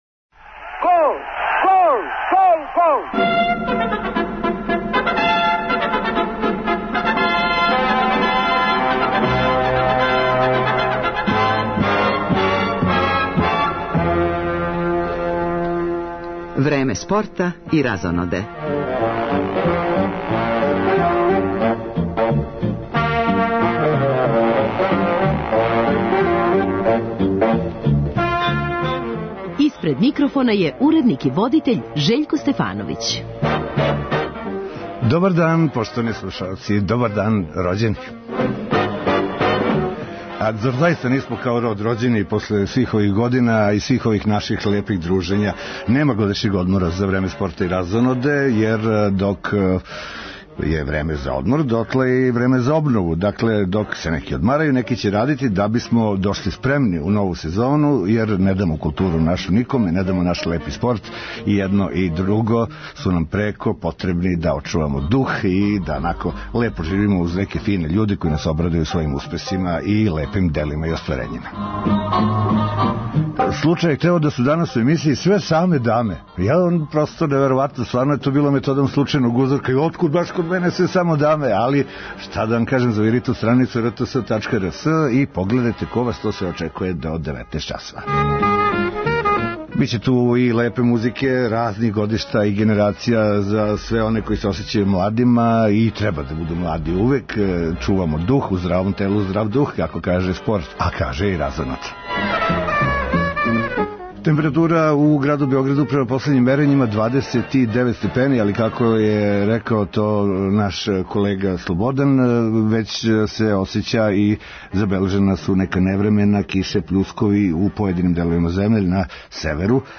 Једна од њих је гошћа овог издања породичног магазина Радио Београда 1.